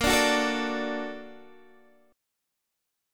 A#+M7 chord